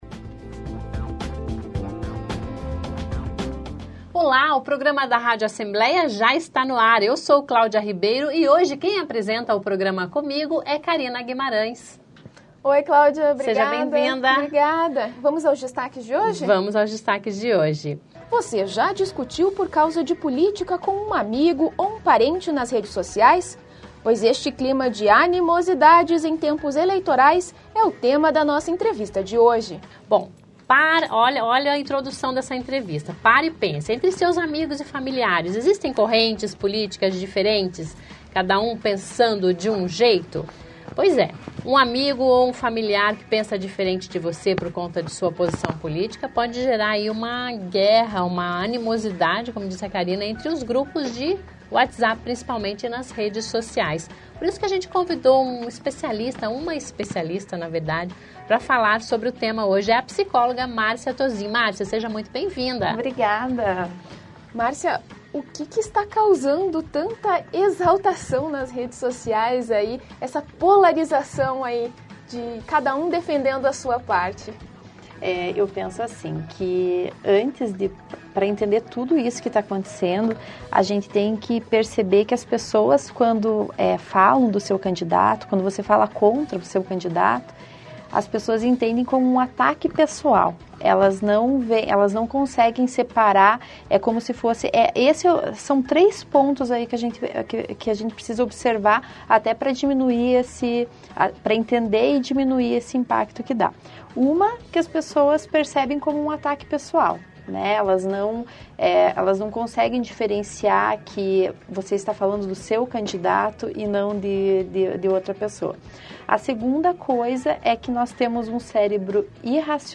Discutiu com amigos, parentes por política? Sim? Então, essa entrevista é pra você!